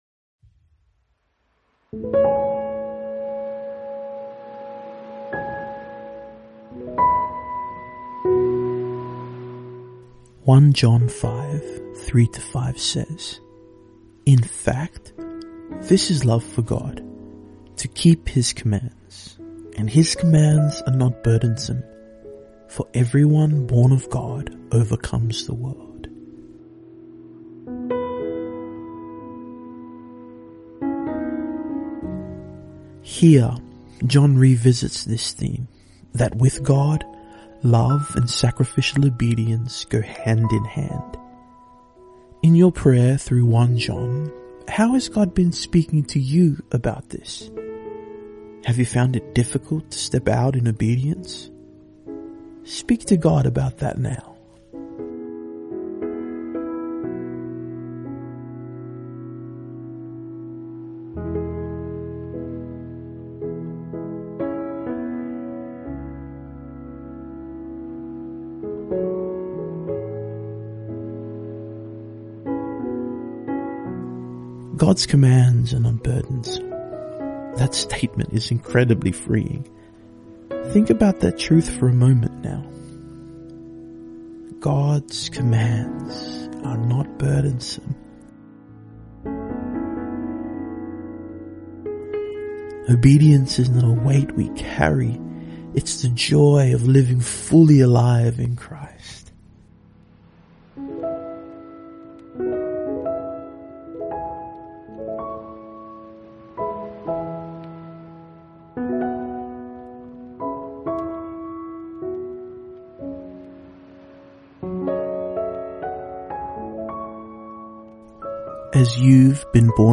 After you have completed today’s reading from the Bible, we encourage you to set aside a moment to listen along to the guide provided as we pray and allow God to speak to us through His Word.